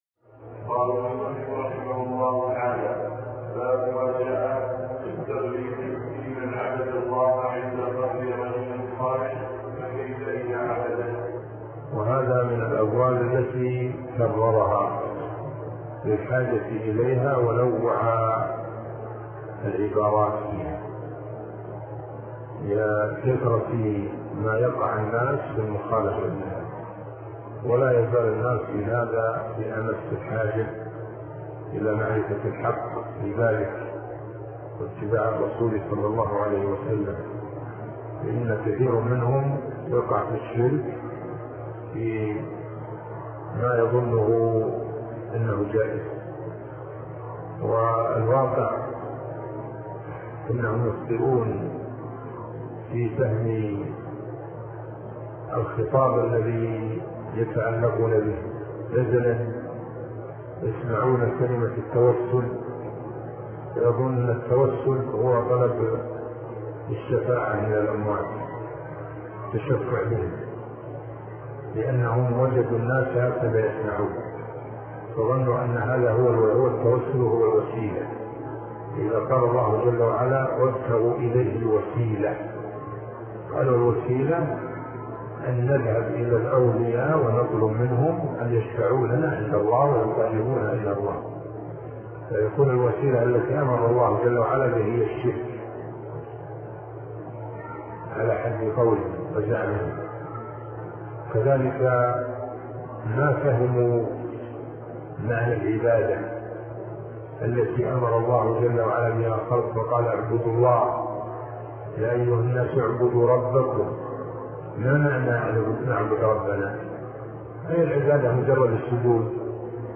عنوان المادة الدرس ( 60) شرح فتح المجيد شرح كتاب التوحيد تاريخ التحميل الجمعة 16 ديسمبر 2022 مـ حجم المادة 32.01 ميجا بايت عدد الزيارات 263 زيارة عدد مرات الحفظ 118 مرة إستماع المادة حفظ المادة اضف تعليقك أرسل لصديق